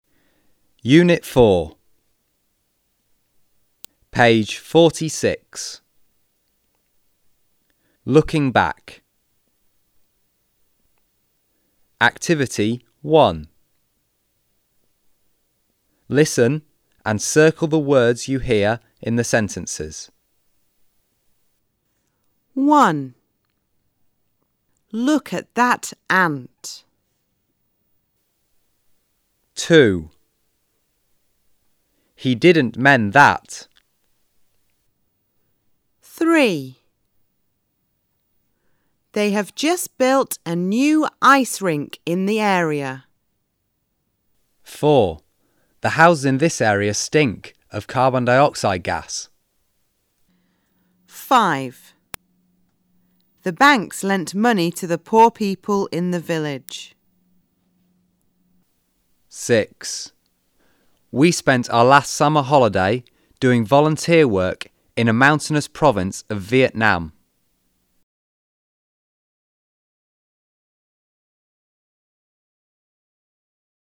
Sách nói | Tiếng Anh 10_Tập 1 (Unit 4)